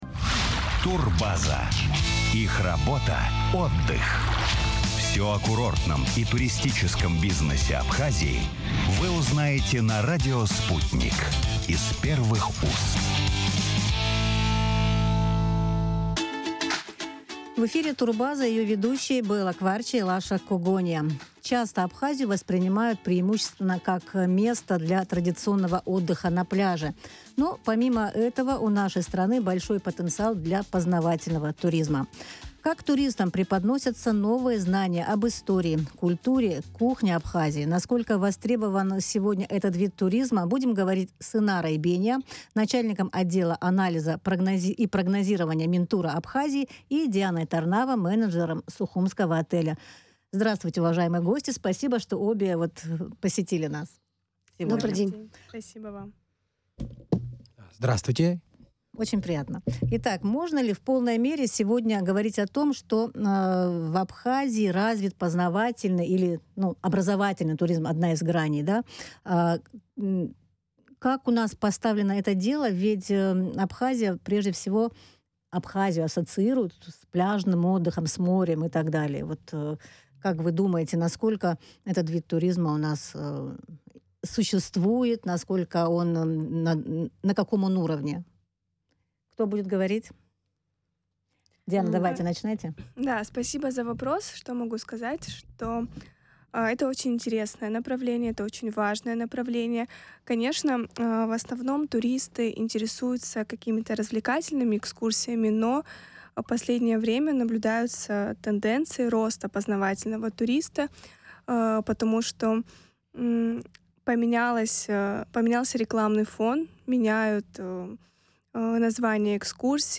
У Абхазии большой потенциал для познавательного туризма. О том, как гостям страны преподносится информация об истории, культуре, кухне, насколько востребован сегодня этот вид туризма, в эфире радио Sputnik говорили с начальником отдела...